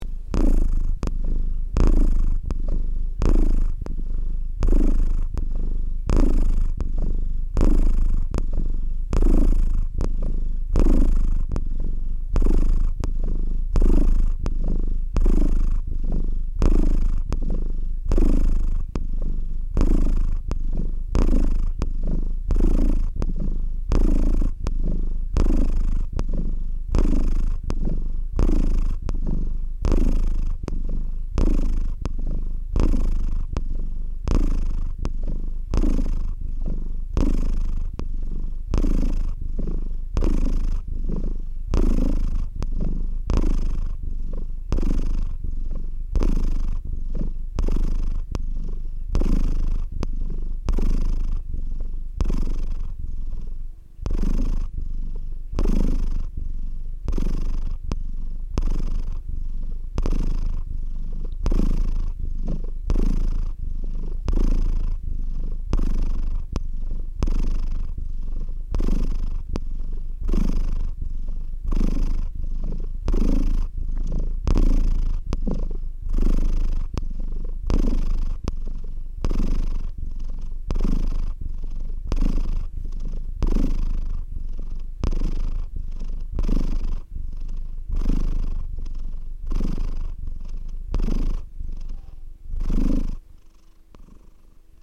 Cat Purr 1
cat-purr-1.mp3